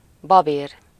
Ääntäminen
IPA : /ˈlɒɹ.əl/